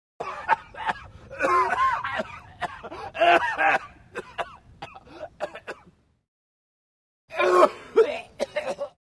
На этой странице собраны различные звуки кашля человека в высоком качестве.
Мужской кашель третий вариант